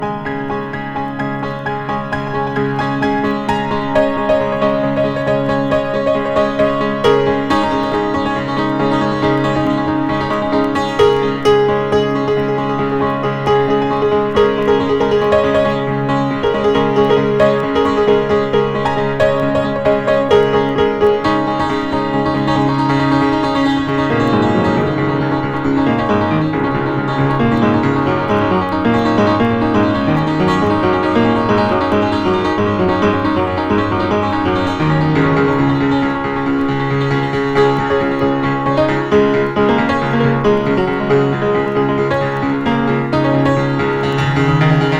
本盤は、ピアノソロ作品集。
ピアノソロとはいえ、奇妙奇天烈っぷりはしっかり刻まれてます。